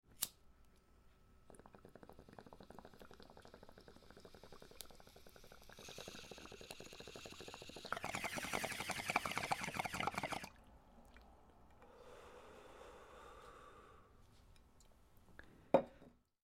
Настоящий звук затяжки бонга